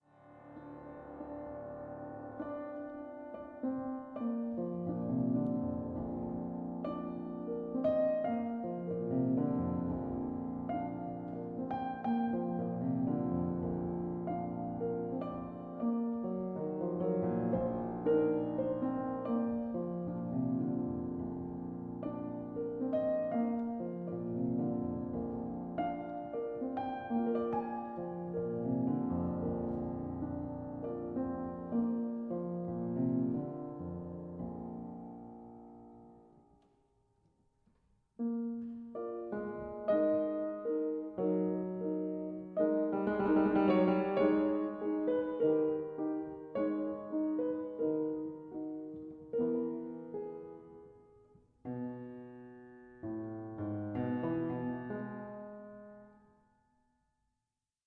piano (1841 Paris Erard, tuned to a historic temperament
19 No 7 in C sharp minor, 'Cello'